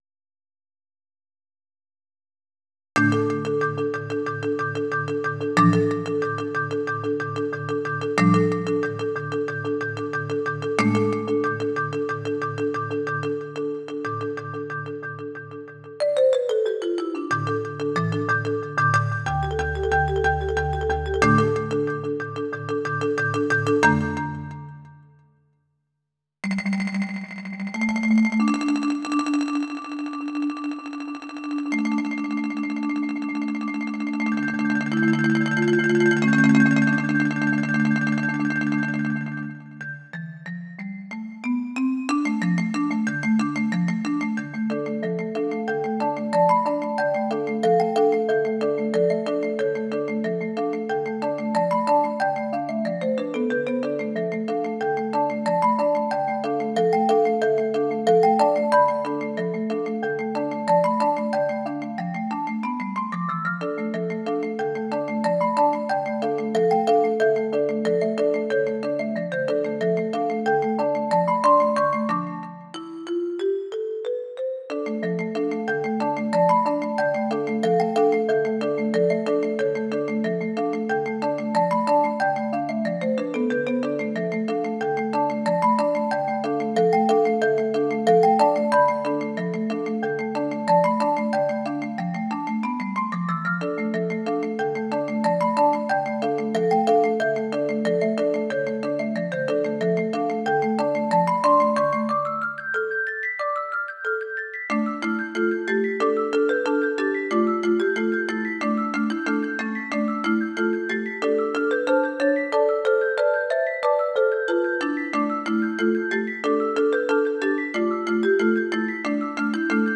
音源は全てVSC-88です。
古い協会旋法を使ってみました。